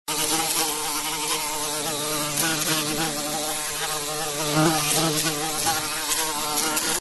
На этой странице собраны разнообразные звуки пчел: от одиночного жужжания до гула целого роя.
Пчела пытается пролететь сквозь оконное стекло